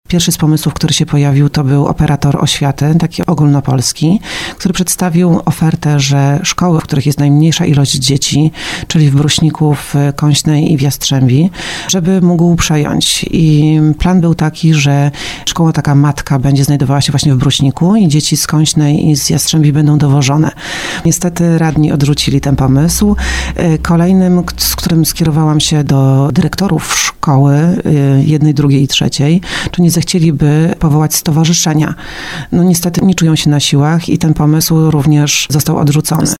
Burmistrz Ciężkowic Jowita Jurkiewicz-Drąg, która była gościem programu Słowo za Słowo wyjaśnia, że do oświaty gmina musi dopłacać 10 milionów złotych, co staje się coraz większym obciążeniem dla budżetu. Jak twierdzi, mała liczba uczniów przekłada się na niskie subwencje oświatowe, które nie pokrywają bieżących kosztów.
Na antenie RDN Małopolska powiedziała również, że ma już pomysł na zagospodarowanie budynku po zlikwidowanej szkole.